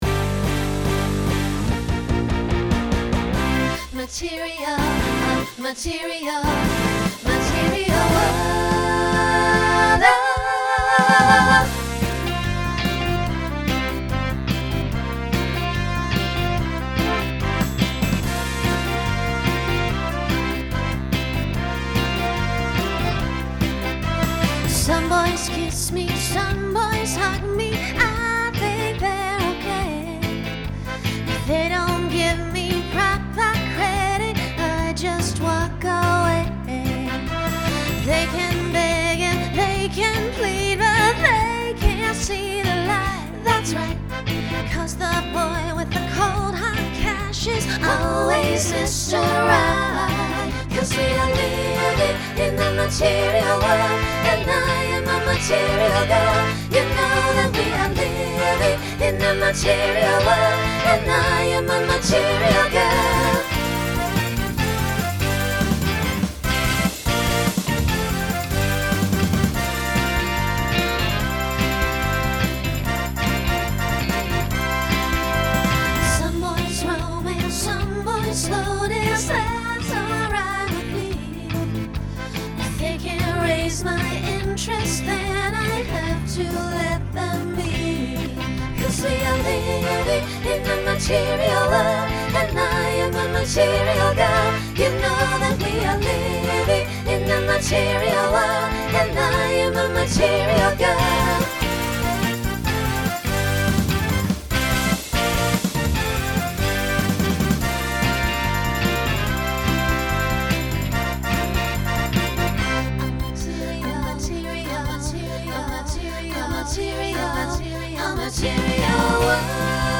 Pop/Dance
Opener Voicing SSA